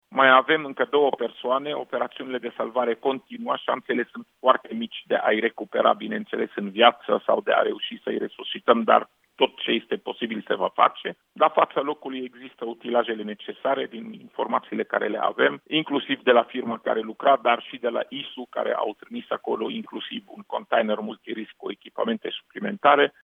O persoană a fost recuperată de salvatori, dar a fost declarată decedată, a spus la Europa FM Raed Arafat, șeful Departamanetului pentru Situații de Urgență, structură din cadrul Ministerului Afacerilor Interne.